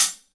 Q Hat clmx1 mf.WAV